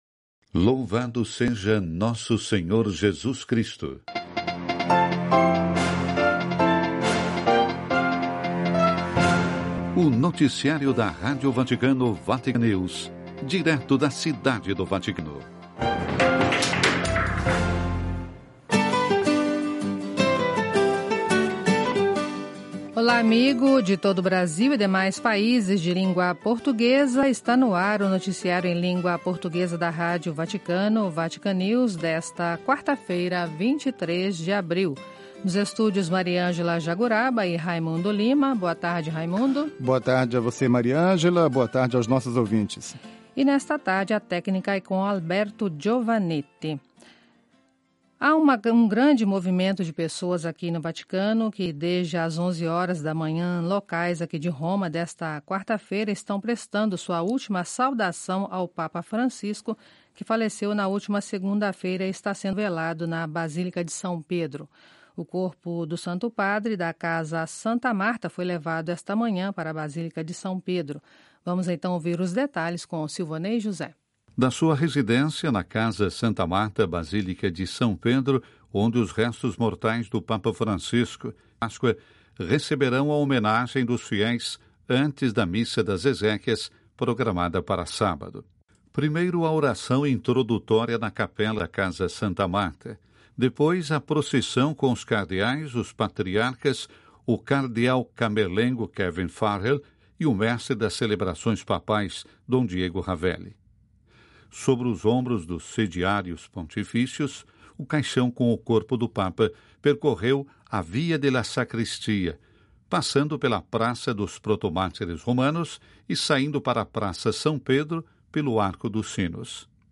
Noticiário (12:00 CET).